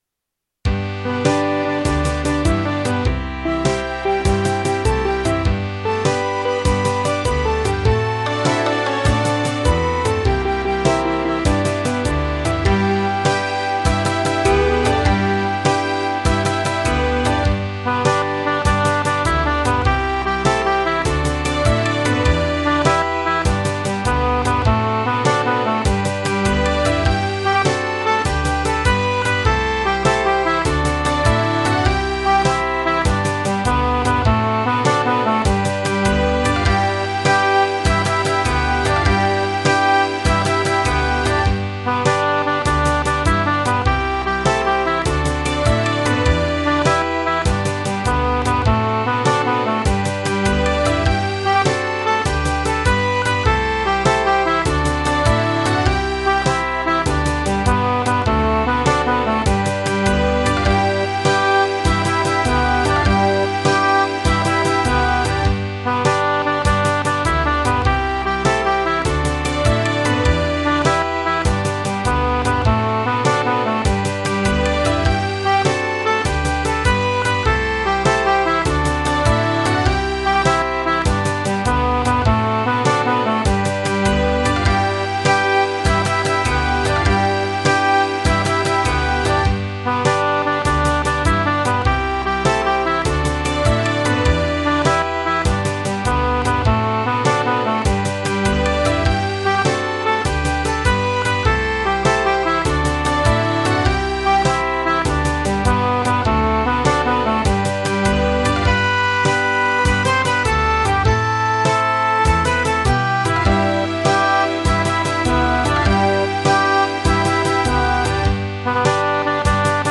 instrumental
key=G T=100
instrumentalオーディオ（カラオケ）ダウンロード・保存　ozashiki-g-melody.mp3